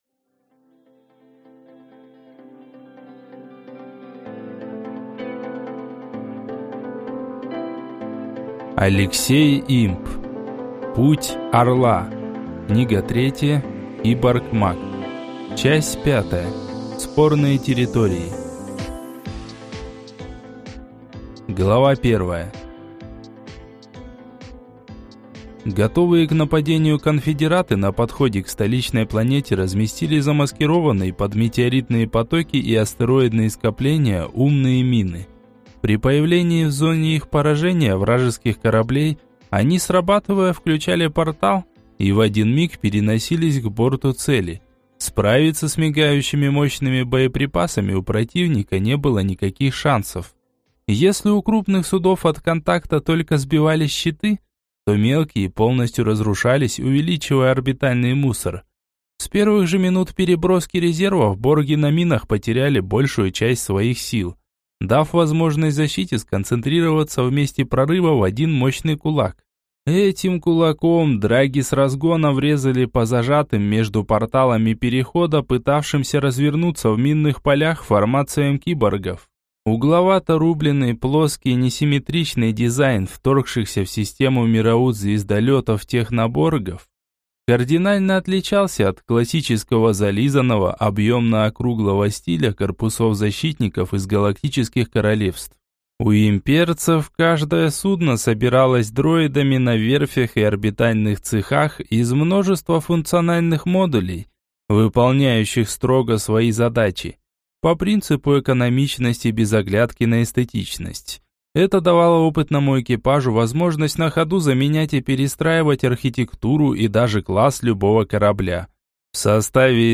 Аудиокнига Путь Орла. Книга 3. Киборг-маг | Библиотека аудиокниг